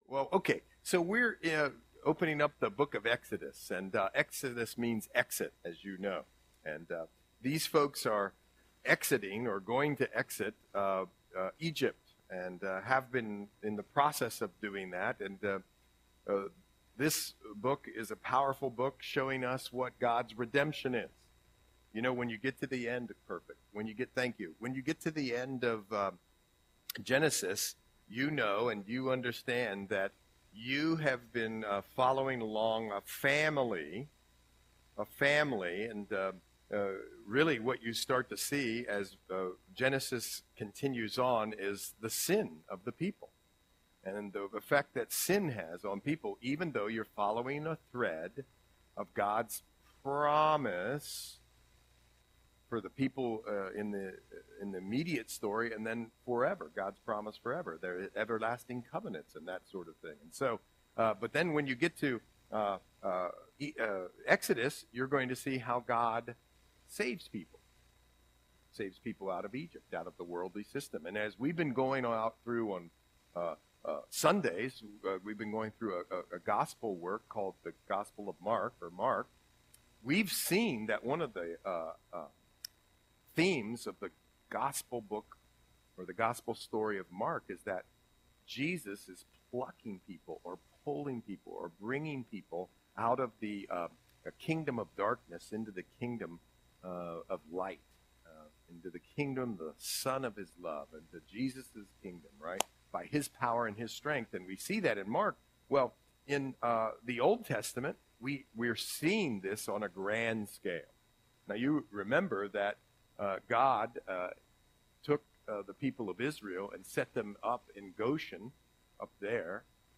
Audio Sermon - November 13, 2024